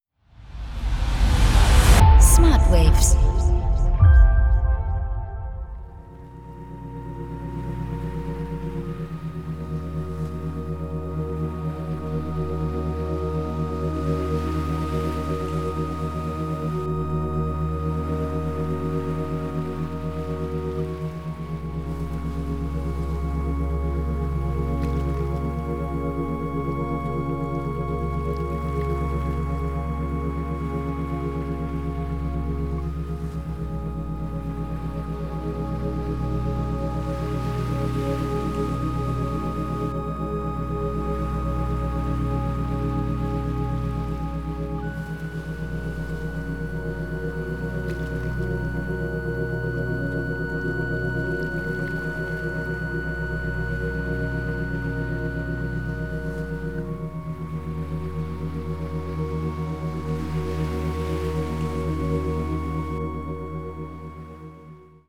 • Methode: Isochrone Beats
• Frequenz: 7,83 Hertz